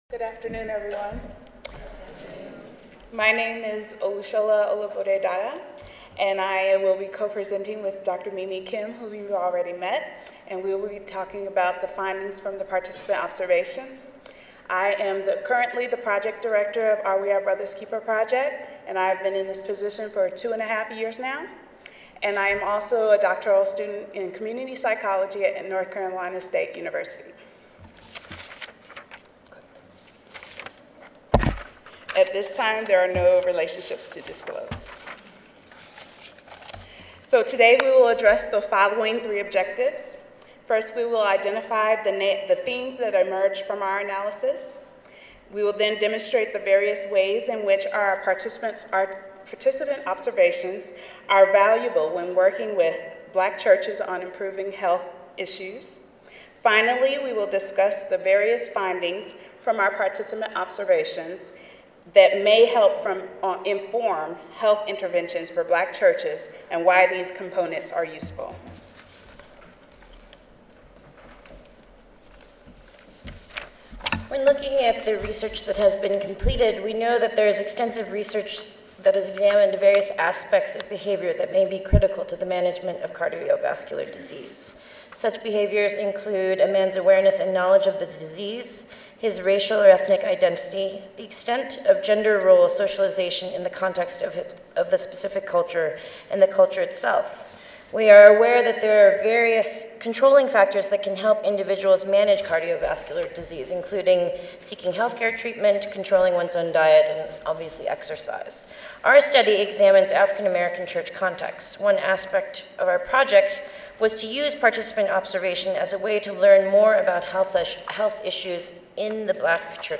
Oral